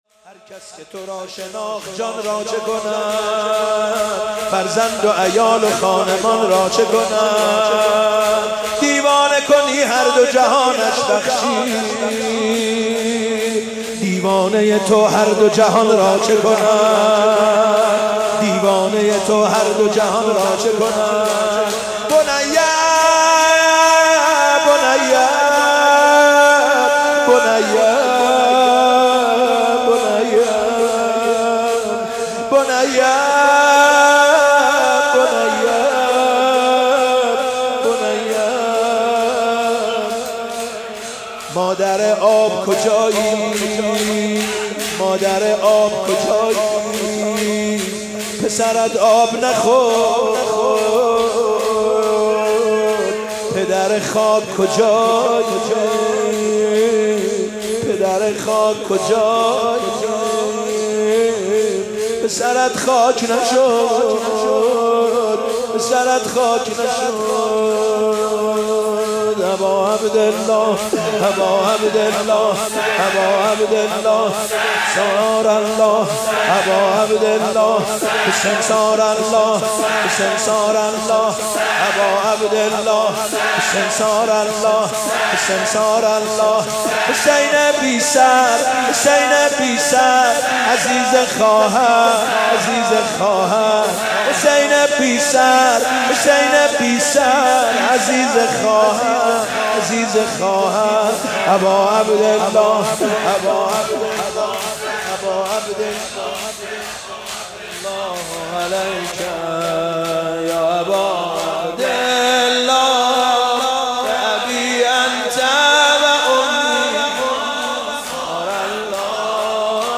فاطمیه 96 - اردبیل - شور - صلی الله علیک یا ابا عبدالله